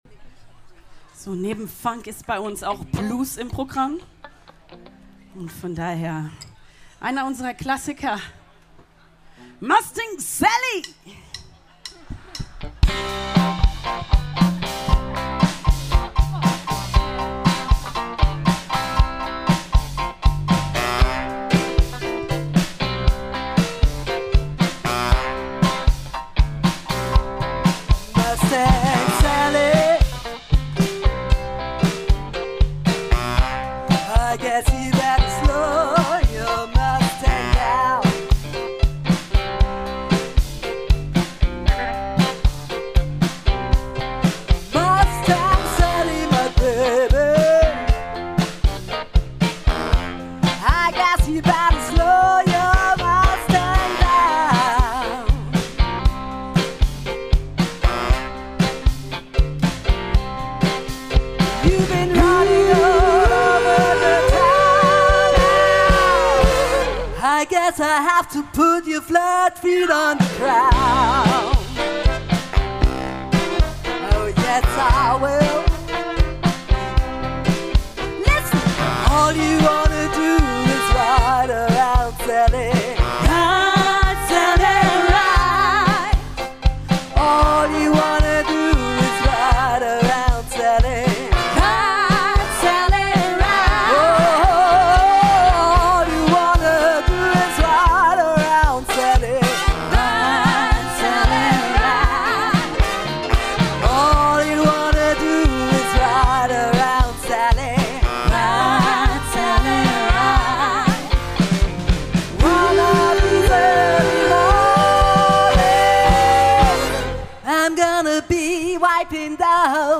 · Genre (Stil): Soul
· Kanal-Modus: stereo · Kommentar